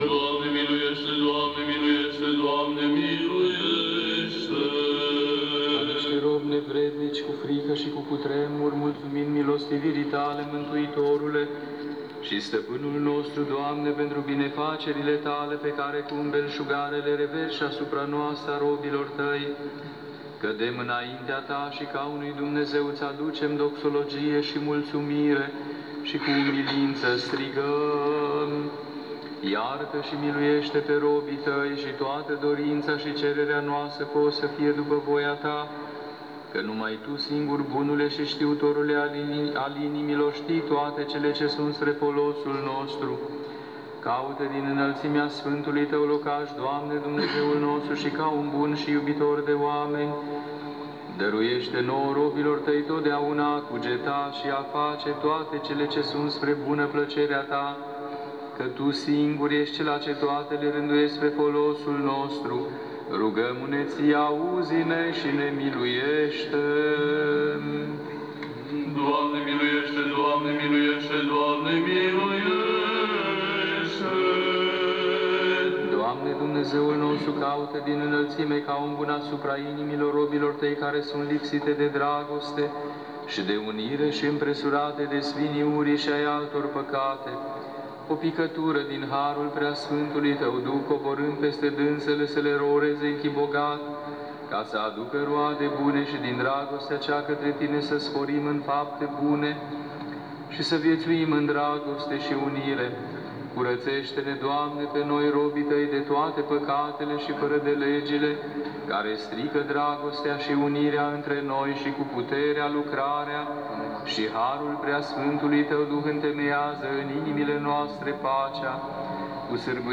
Cuvinte de învățătură - Să se umple gurile noastre de lauda Ta Doamne, ca să lăudăm slava Ta
Rugăciune la Sfânta Liturghie